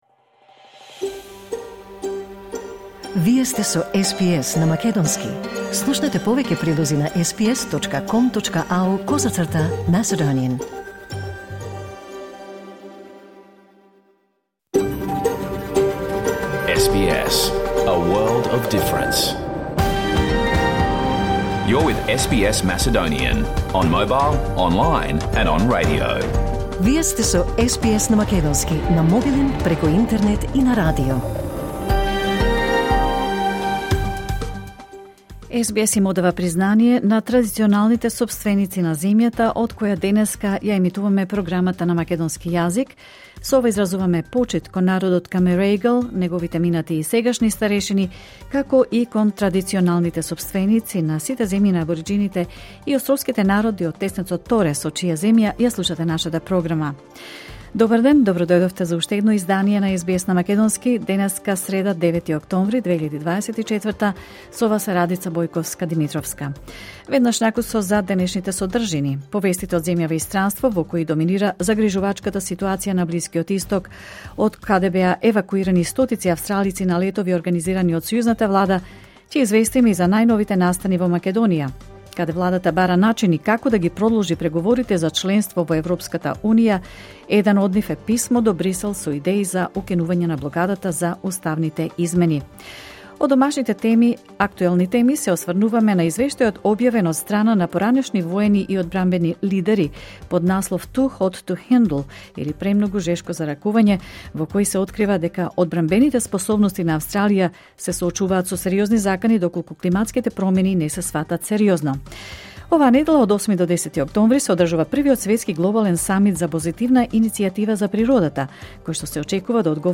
SBS Macedonian Program Live on Air 9 October 2024